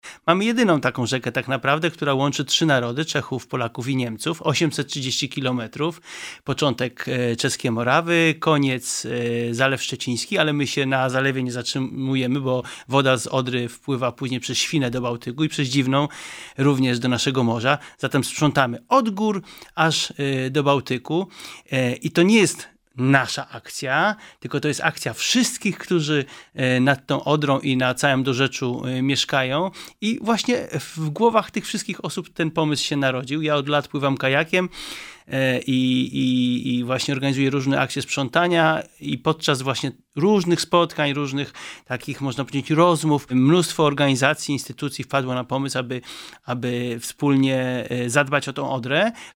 W studiu Radia Rodzina